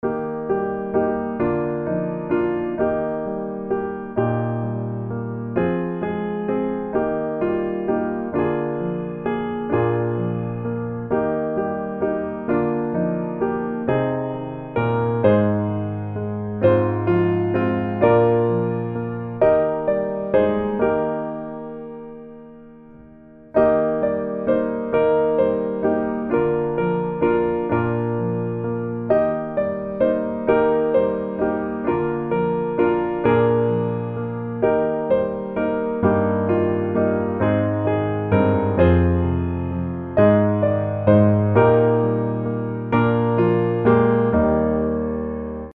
Eb Majeur